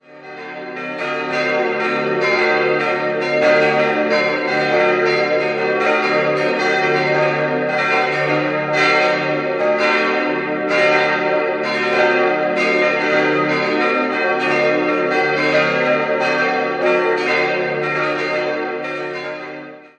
Erst im Jahr 1877 errichtete man den Kirchturm. 5-stimmiges erweitertes Salve-Regina-Geläute: es'-g'-b'-c''-es'' Alle Glocken wurden von der Gießerei Rüetschi (Aarau) gegossen, Glocke 4 erst 1981, die anderen vier schon im Jahr 1876.